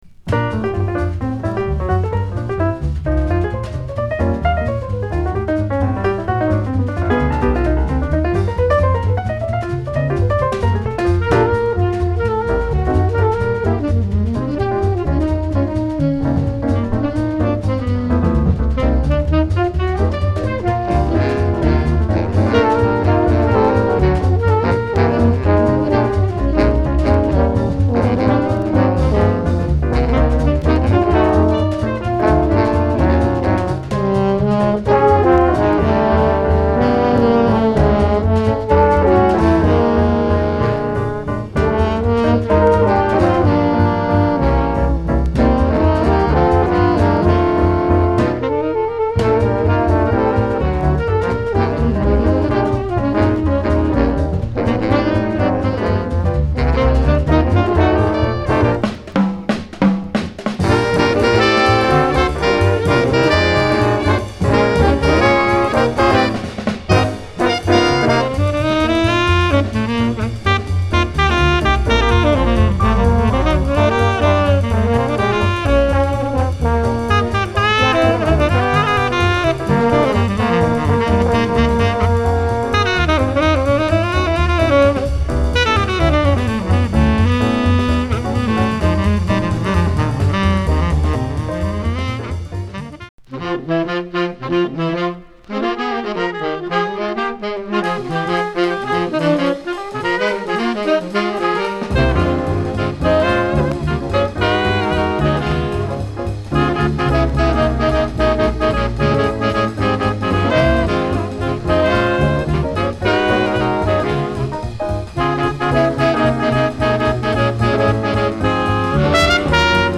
Disk：sideA:VG+ sideB:EX
軽いノイズ散発します。